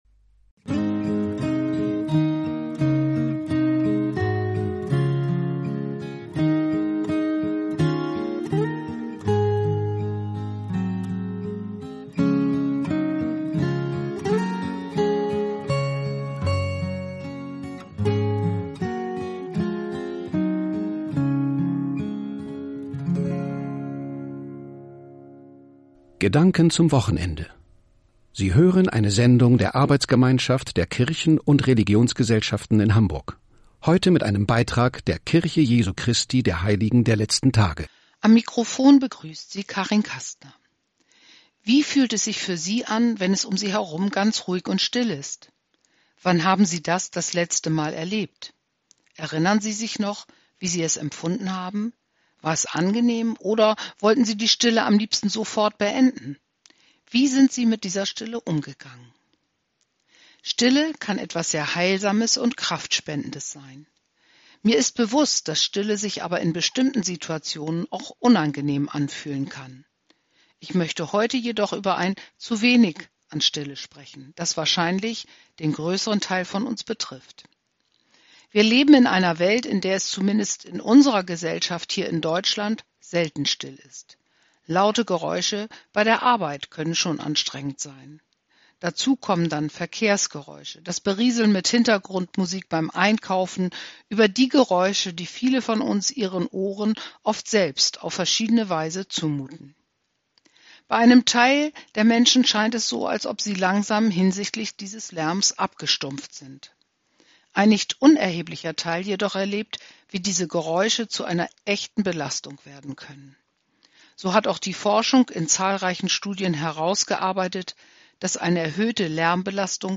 Radiobotschaften vom Mai, Juni und Juli